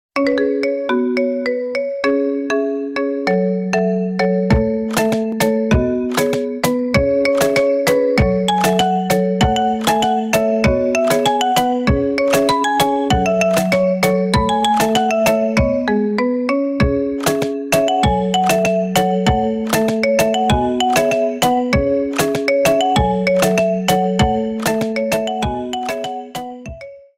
Category: Iphone Remix Ringtones